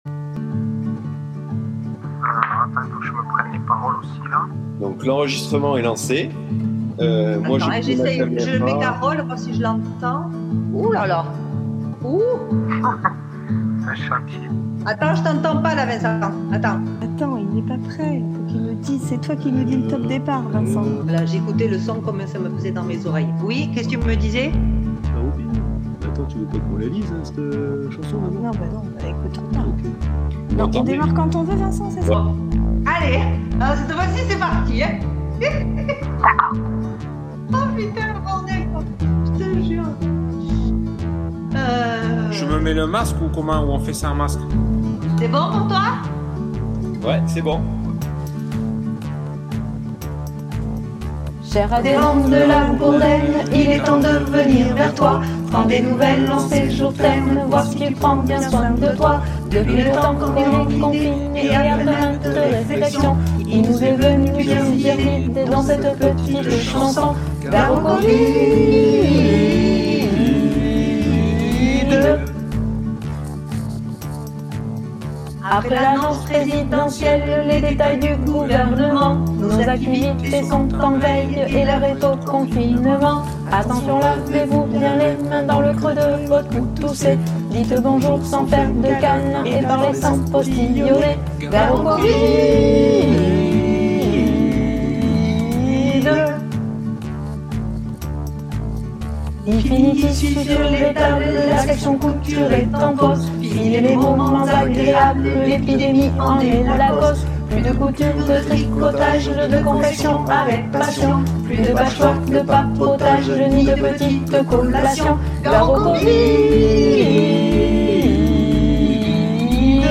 Gare au Covid par le bureau de La Bourdenne
Bravo aux choristes du Bureau!
Bravo à la chorale et félicitations aux paroliers